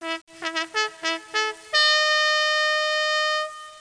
horn8.mp3